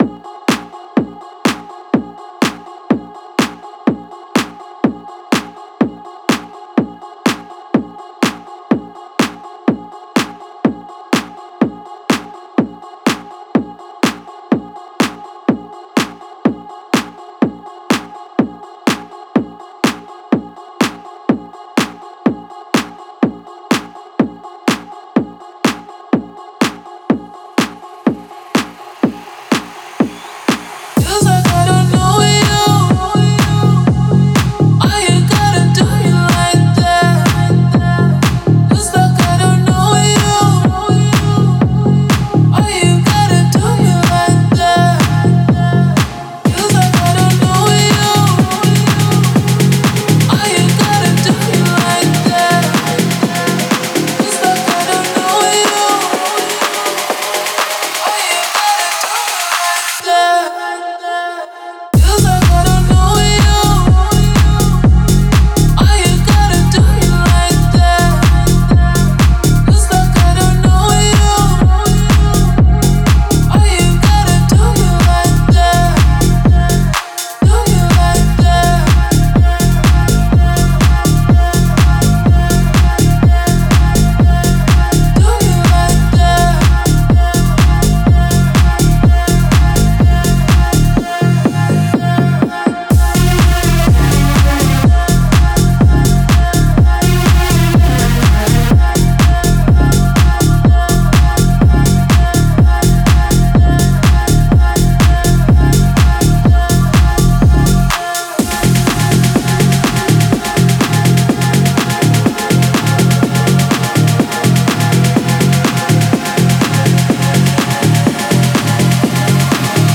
🎹 Key: F Minor🥁 BPM: 124
💡 Genre: Tech House